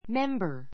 mémbə r メ ンバ